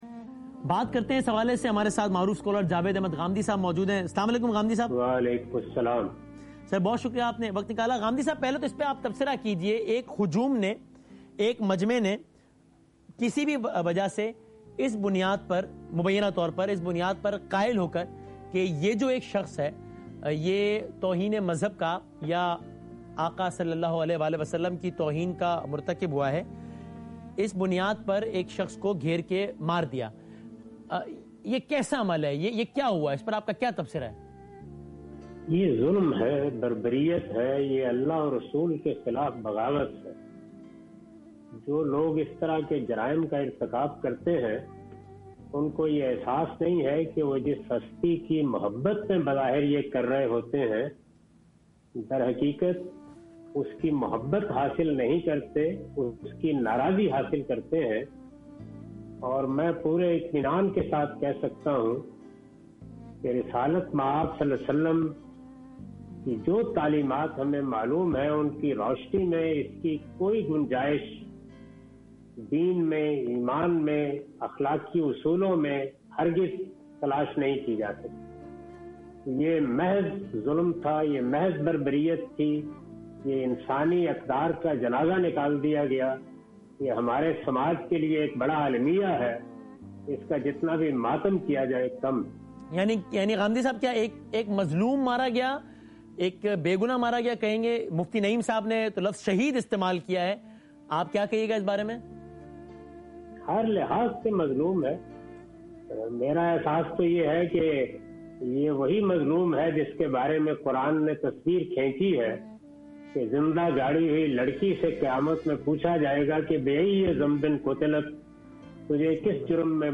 Category: TV Programs / ARY /
In this program Javed Ahmad Ghamidi discusses about "Who is responsible for Mashal Khan's murder?" in program 11th Hour with Waseem Badami Kay Sath on ARY News on 17th April, 2017.